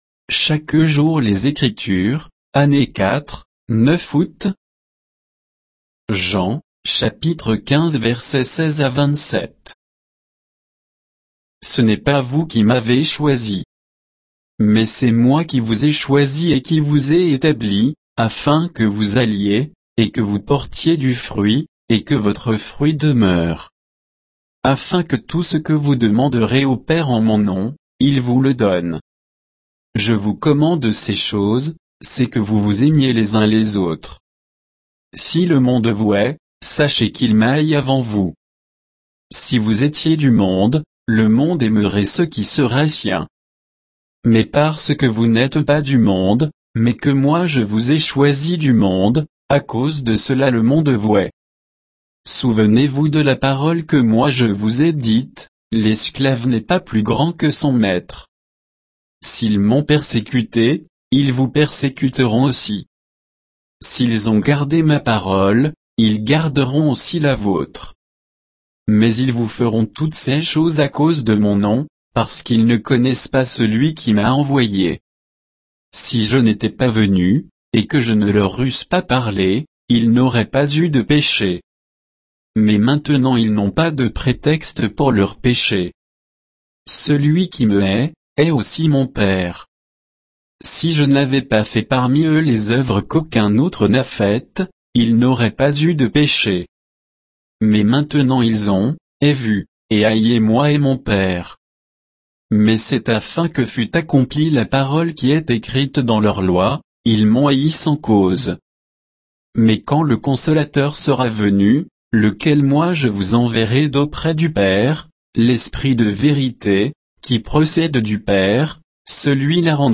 Méditation quoditienne de Chaque jour les Écritures sur Jean 15, 16 à 27